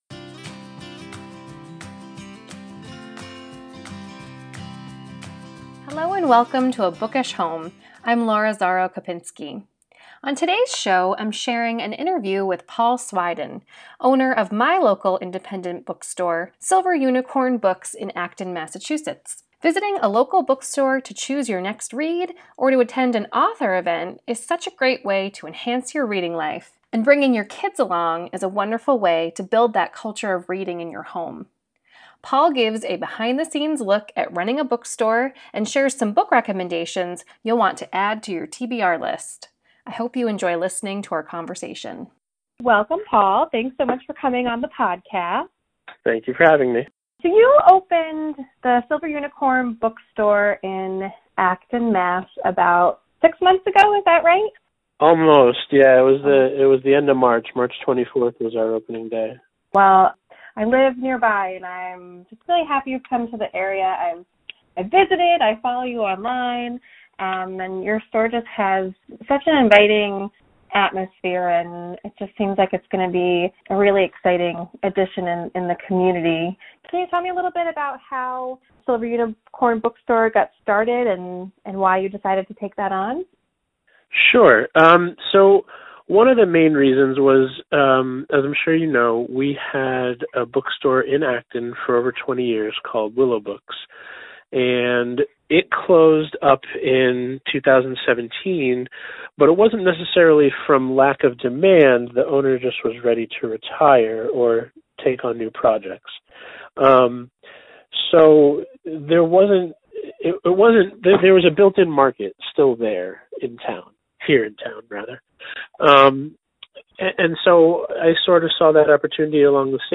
Ep 2: A Conversation